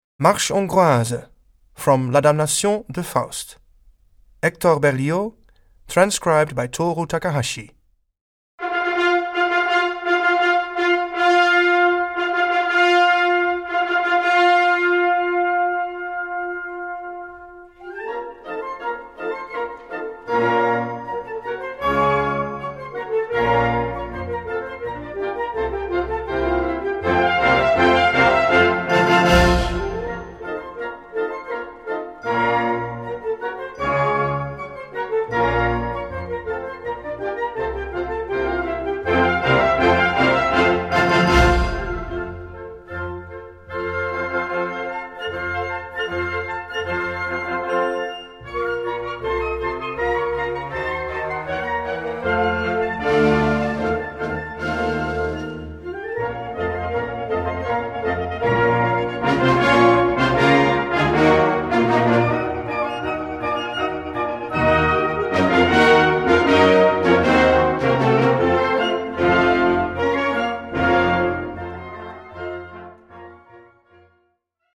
Key: B-flat minor (original key: A minor)